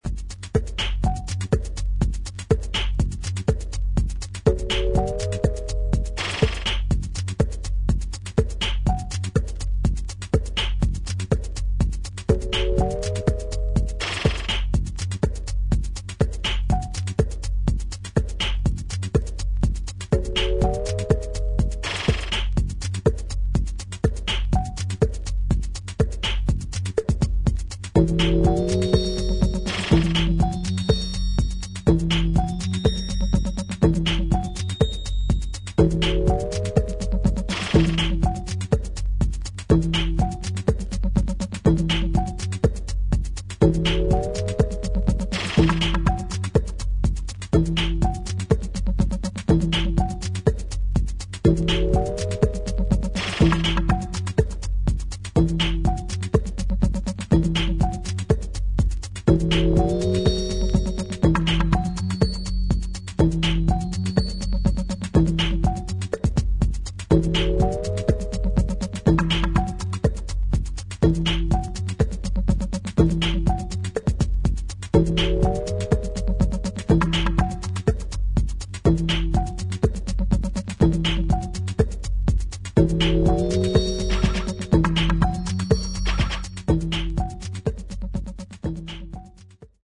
低音の音圧の野太さにも驚かされる一枚。